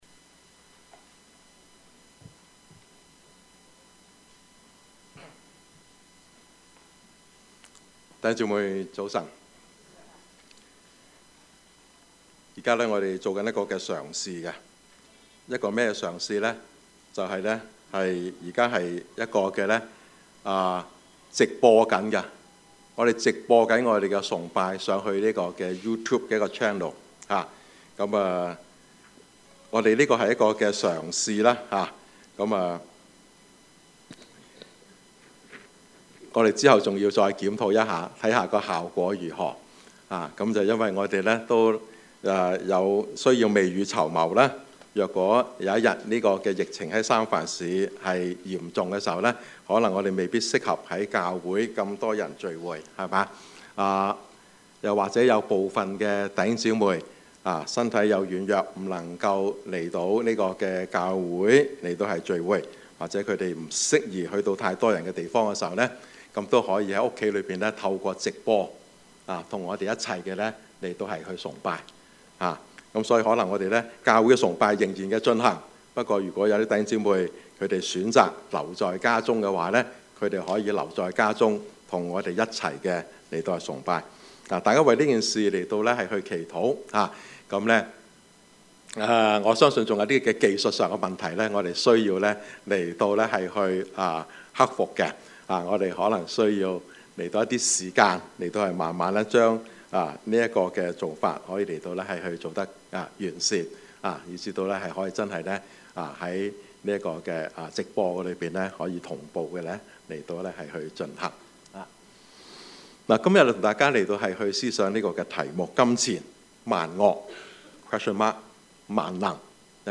Service Type: 主日崇拜
Topics: 主日證道 « 陳納德將軍 相見好同住難 »